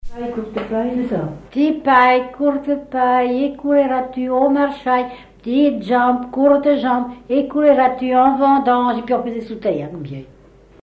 Thème : 0078 - L'enfance - Enfantines - rondes et jeux
Fonction d'après l'analyste formulette enfantine : sauteuse ;
Genre brève
Catégorie Pièce musicale inédite